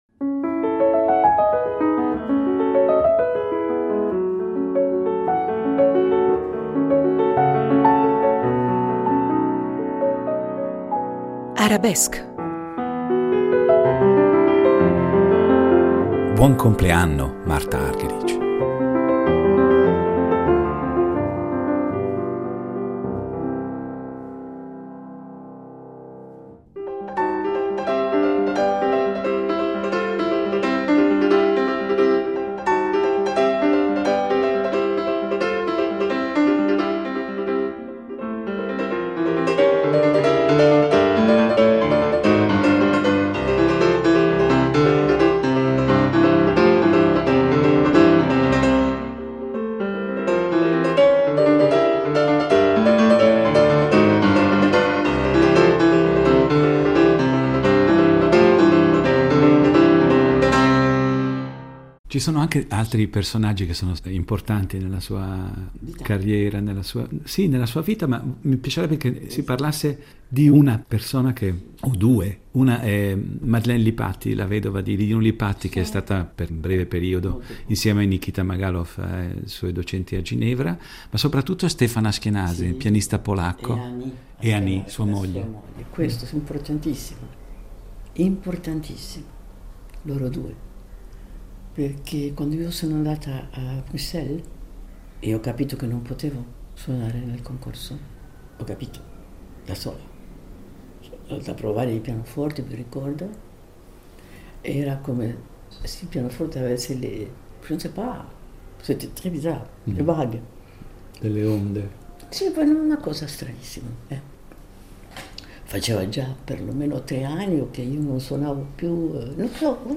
In questa puntata numero 102 di "Arabesque" siamo molto orgogliosi di offrire al nostro pubblico la terza parte di una delle rare interviste concesse dalla pianista argentina.